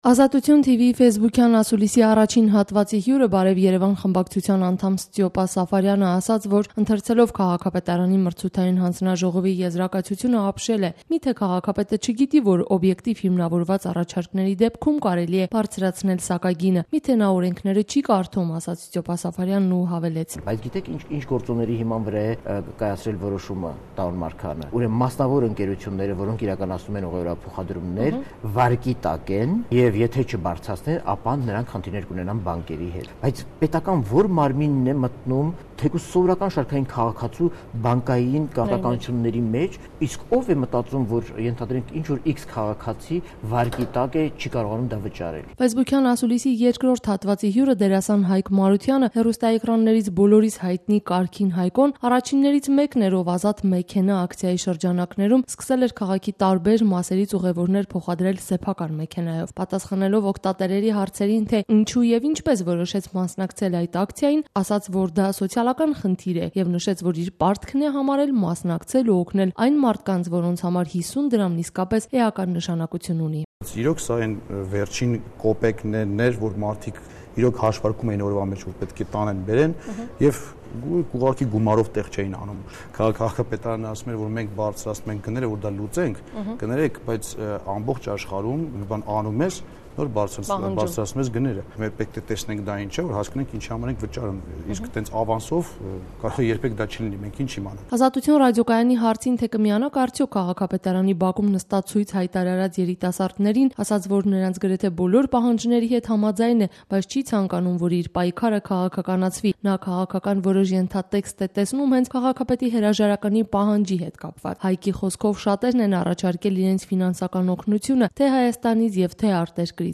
Ֆեյսբուքյան ասուլիս Ստյոպա Սաֆարյանի եւ Կարգին Հայկոյի հետ